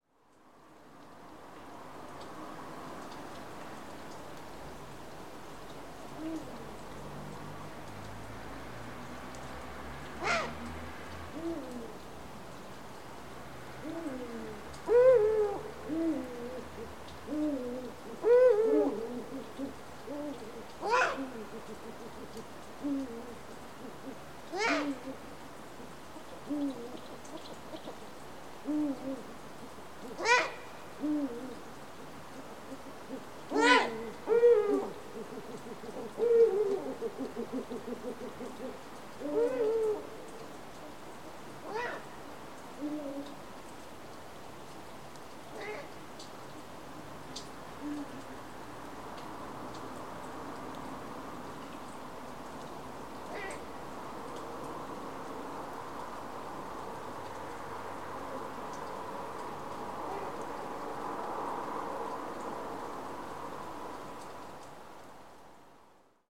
Un Hibou Grand Duc dans la Vienne
chant-bubo-bubo.mp3